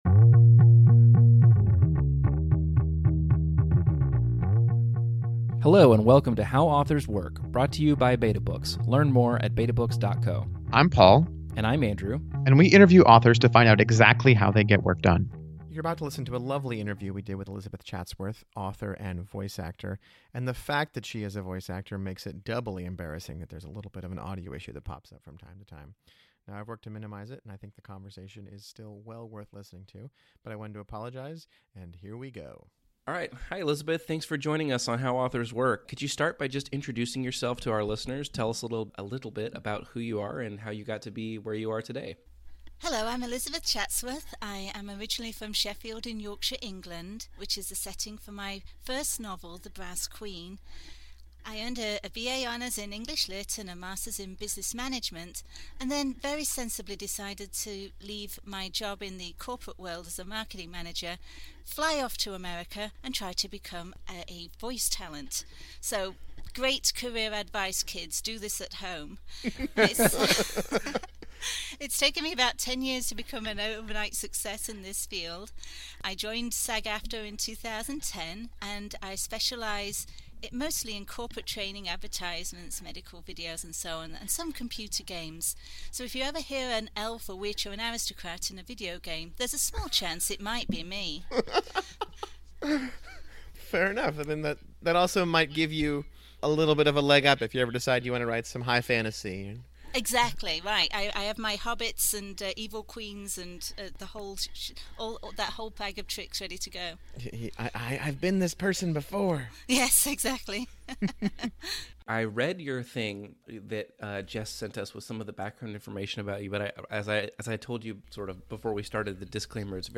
Hear my interview on the “How Authors Work” podcast!